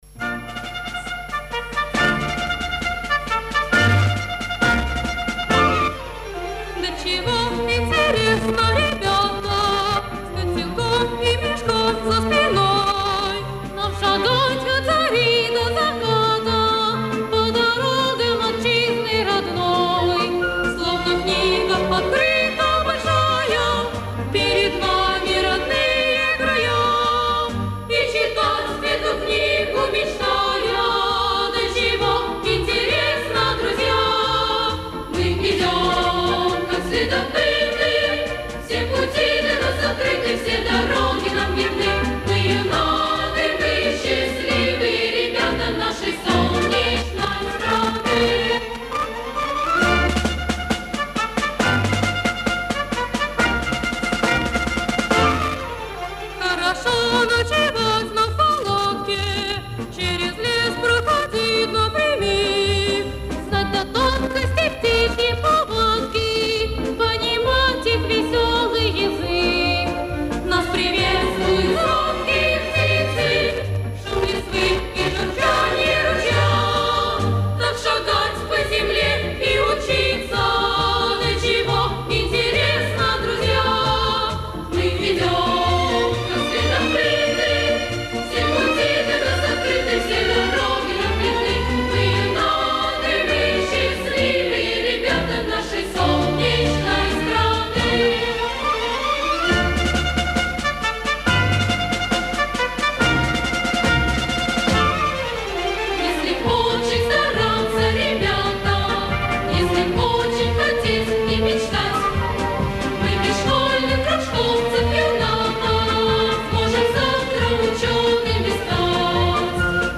Жизнерадостный марш юных натуралистов в другом исполнении.